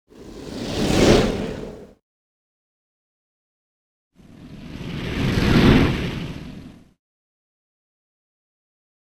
Shark Surface Pass By Sound
animal